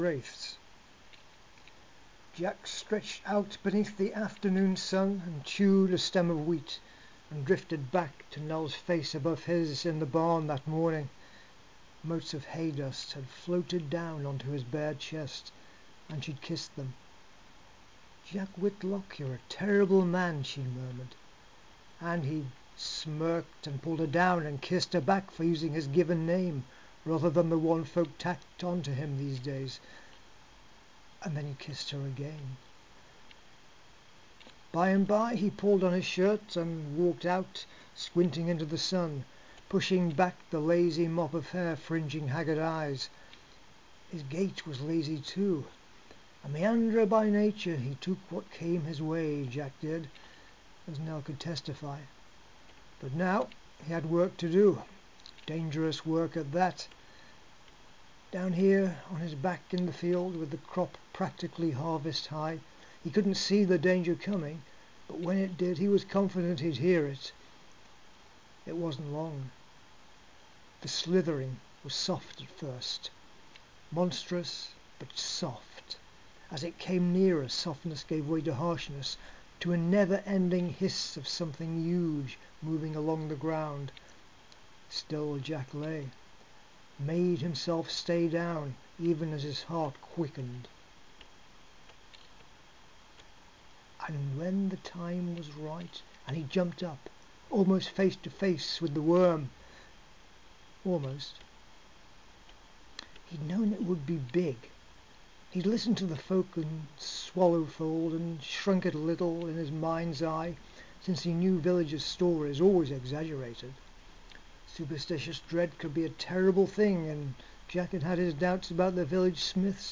with audio by the author